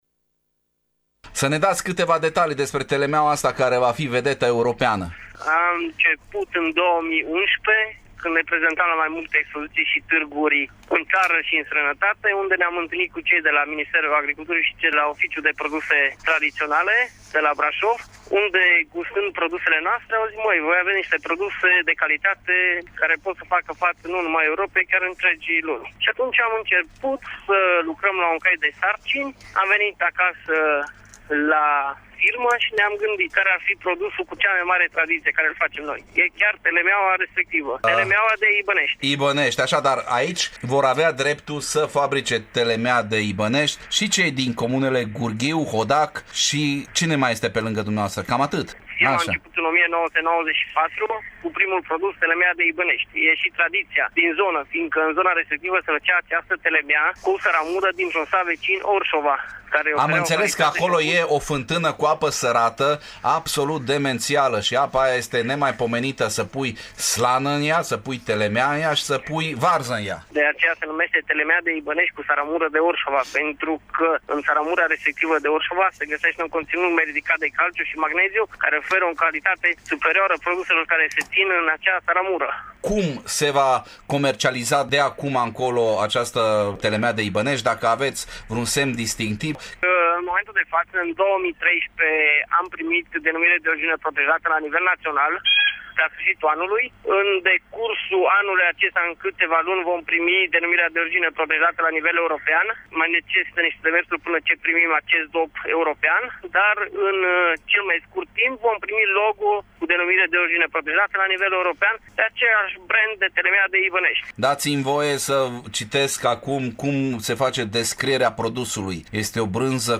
interviu-telemea.mp3